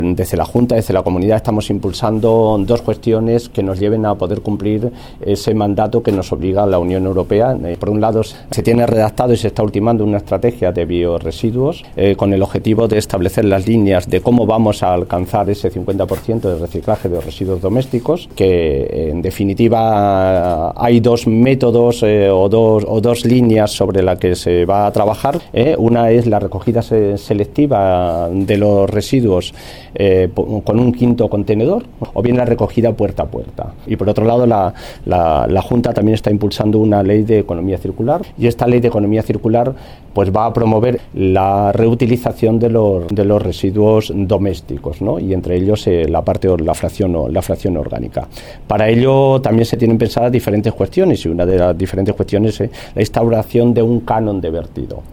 El viceconsejero de Medio Ambiente, Agapito Portillo, habla de las medidas adoptadas por el Gobierno regional para fomentar el reciclaje.